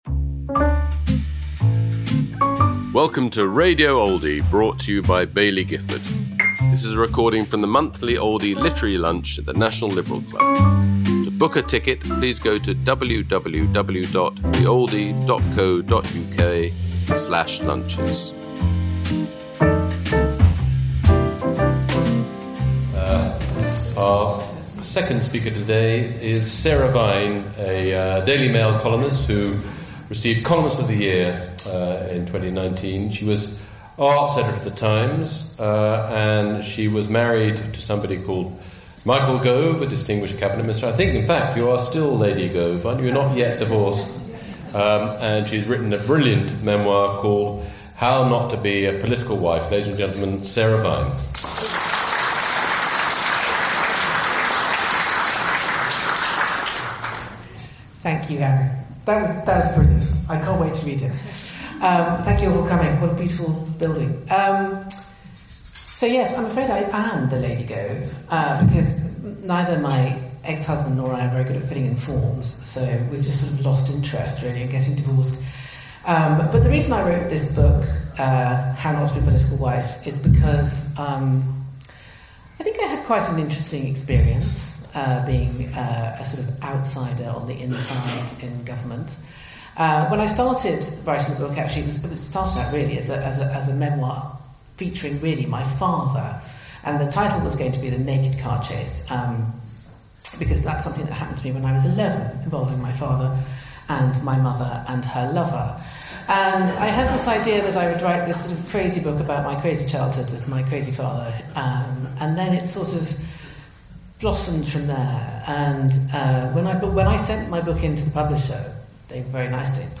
Sarah Vine speaking about her new book, How Not to be a Political Wife, at the Oldie Literary Lunch, held at London’s National Liberal Club, on November 4th 2025.